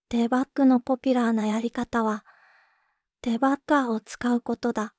voicevox-voice-corpus
voicevox-voice-corpus / ROHAN-corpus /WhiteCUL_かなしい /ROHAN4600_0037.wav